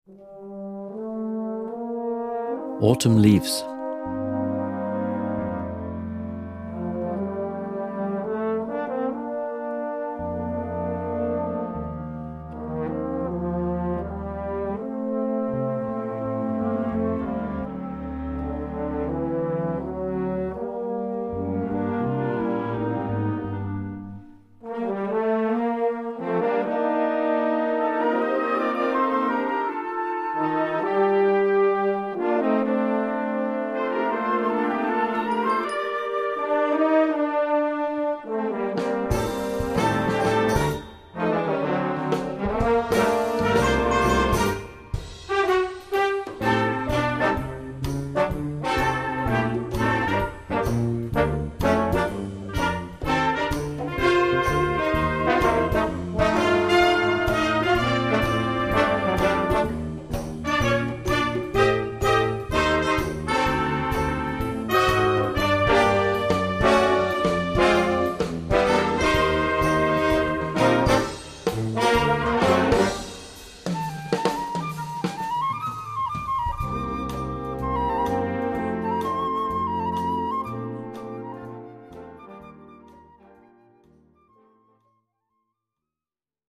Gattung: Jugendwerk
Besetzung: Blasorchester
Ab 7-stimmiger Besetzung spielbar.